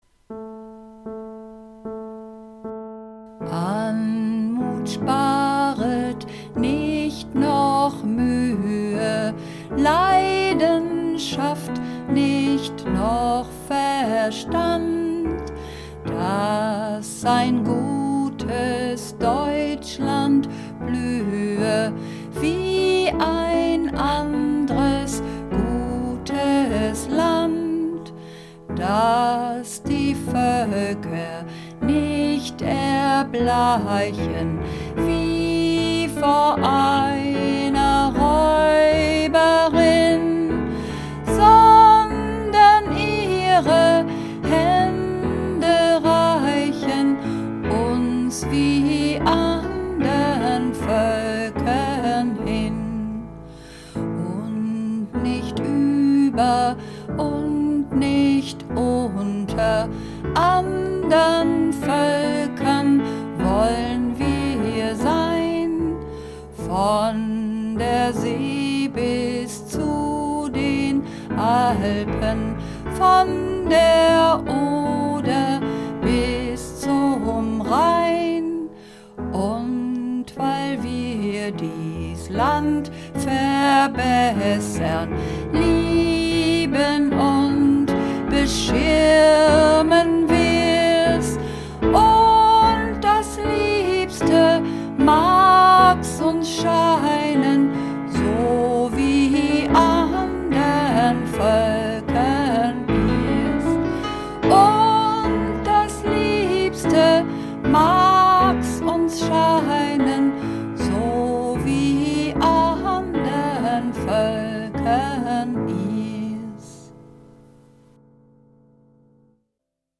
Übungsaufnahmen - Kinderhymne
Kinderhymne (Sopran und Alt - Hoch)
Kinderhymne__2_Sopran_Hoch.mp3